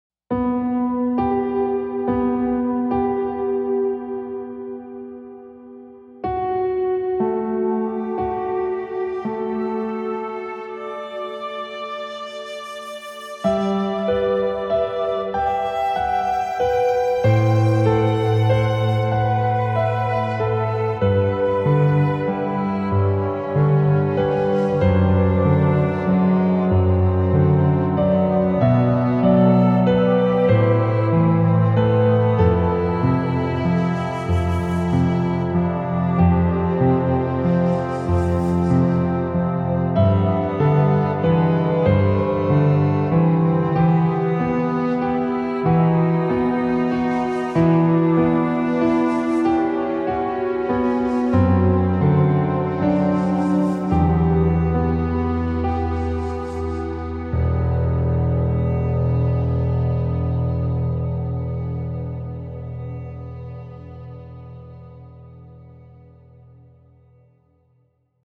Tragic Hero (piano and solo instrument)
Piano-sad.MP3